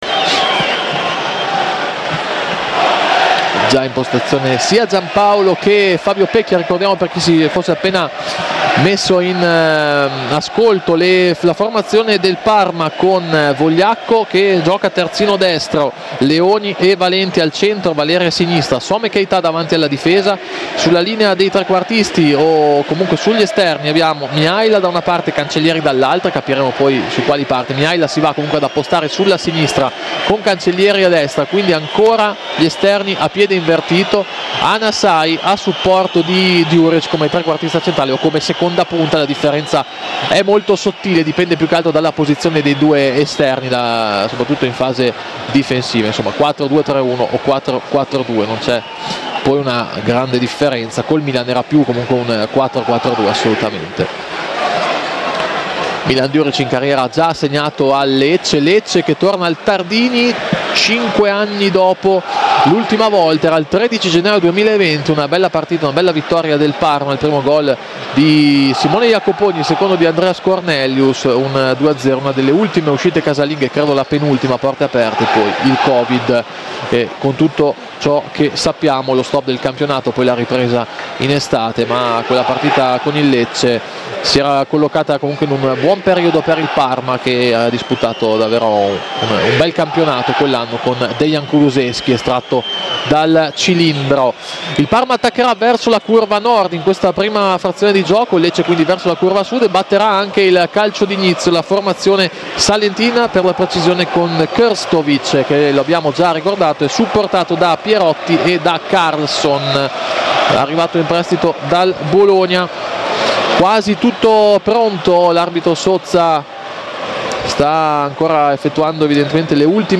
Radiocronache Parma Calcio Parma - Lecce 1° tempo - 31 gennaio 2025 Jan 31 2025 | 00:50:16 1x 00:00 / 00:50:16 Subscribe Share RSS Feed Share Link Embed